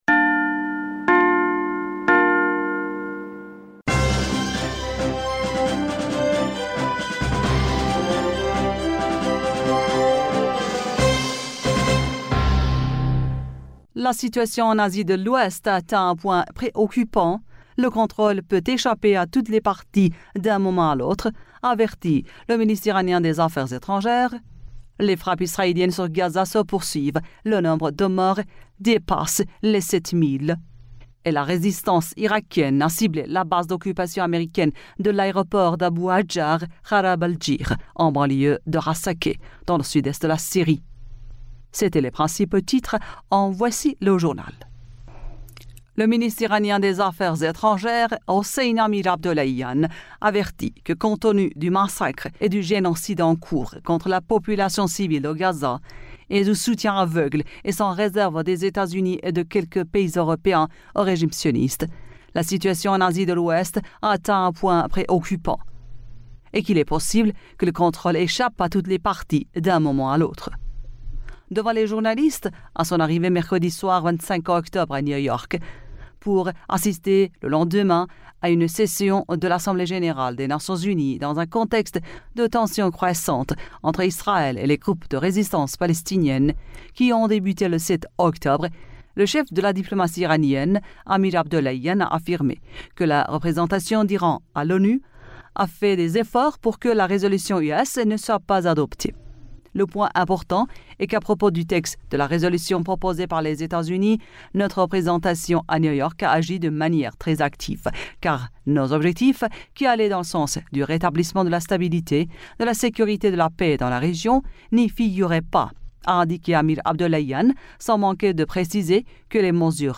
Bulletin d'information du 26 Octobre 2023